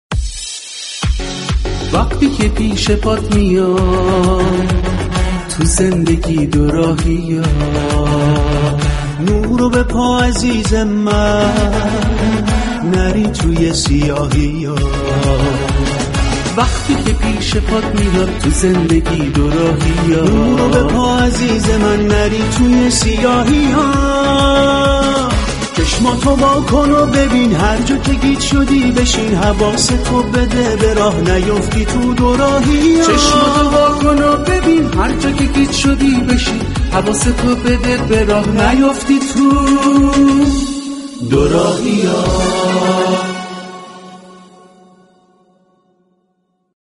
دراین مسابقه زنده پرهیجان شركت كننده با پاسخ به سوالات دو گزینه ای با مراحل مرمت بناهای تاریخی آشنا می شود، كه مطرح شدن این سوالات برای شنوندگان این مسابقه نیز جالب و شنیدنی است و آنها را نیز با فوت و فن مرمت آثار تاریخی آشنا می كند.